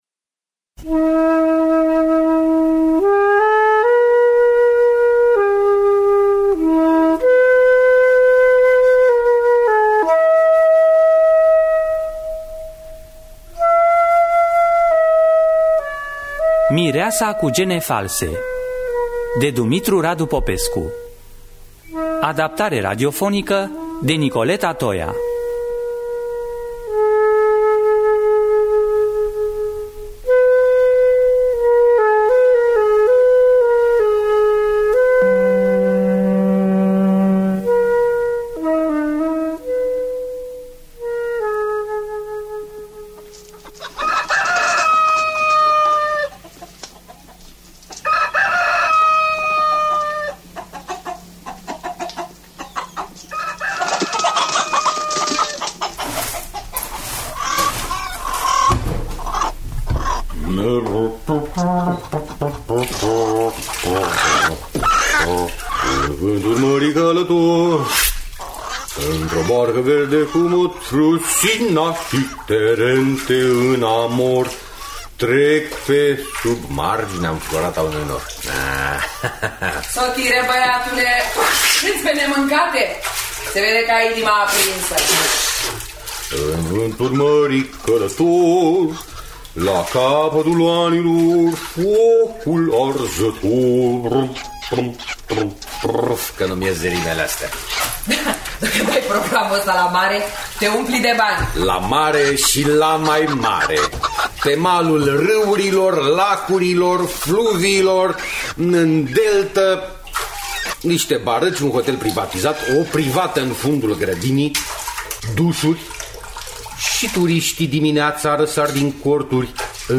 Adaptarea radiofonică
flaut